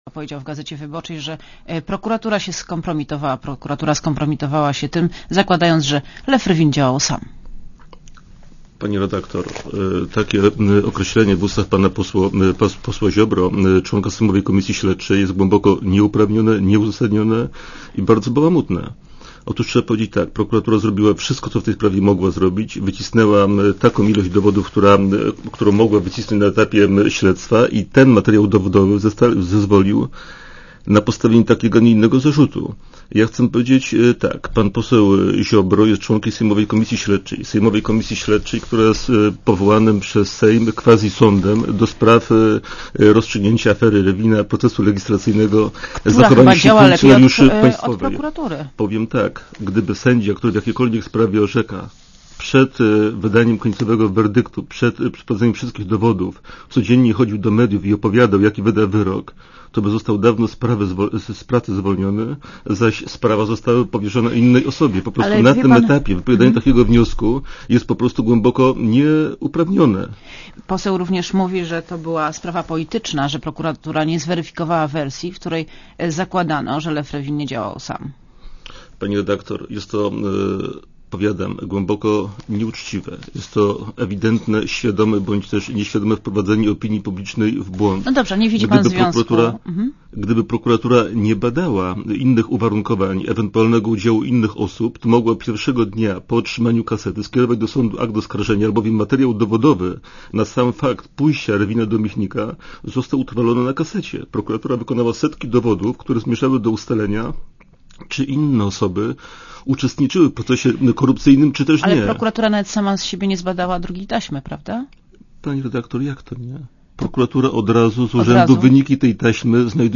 Poseł Ziobro krytykuje sposób działania prokuratury w sposób nieuzasadniony i bałamutny. Gdyby sędzia tak jak pan poseł Ziobro, chodził do mediów i opowiadał jakie wyroki będzie wydawał, od razu zostałby zwolniony z pracy - powiedział w Radiu ZET Kazimierz Olejnik, zastępcą prokuratora generalnego.